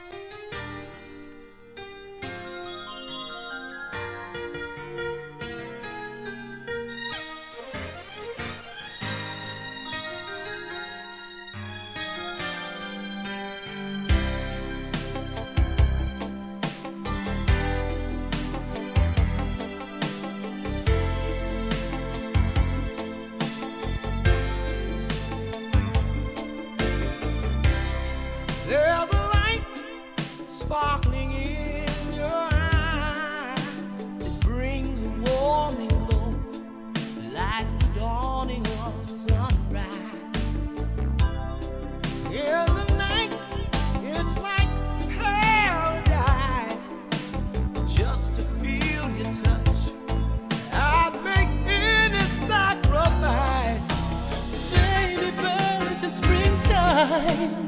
HIP HOP/R&B
GOOD VOCAL & コーラス R&B !!